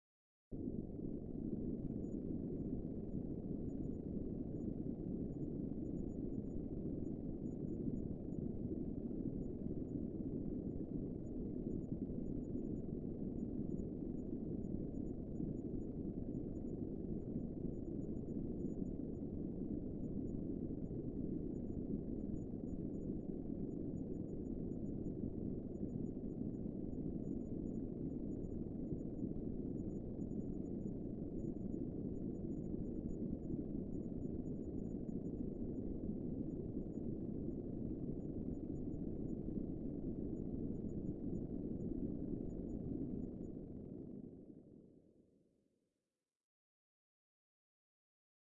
描述：使用vst乐器制作的科幻声音/无需归属 公共领域
标签： 未来 无人驾驶飞机 驱动器 背景 隆隆声 黑暗 冲击 效果 FX 急诊室 悬停 发动机 飞船 氛围 完善的设计 未来 空间 科幻 电子 音景 环境 噪音 能源 飞船 大气
声道立体声